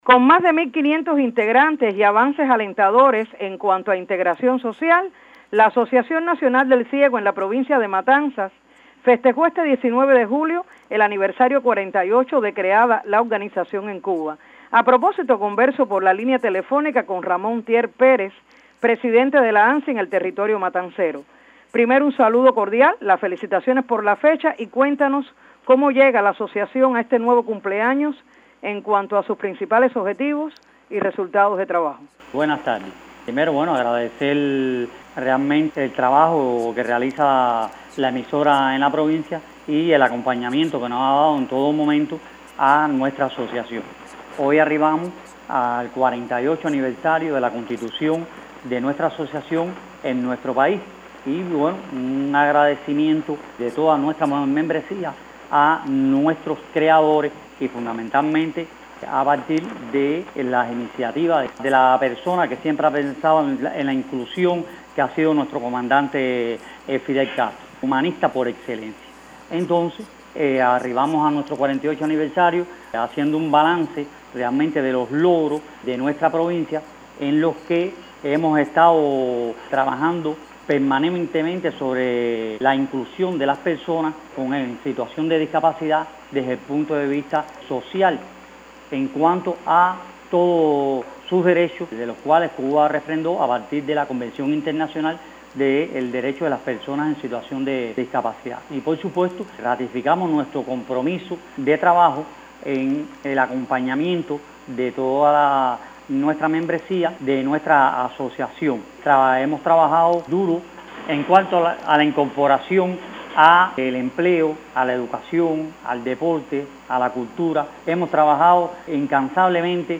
en el contexto de la entrevista que concedió a Radio 26 en ocasión de la fecha.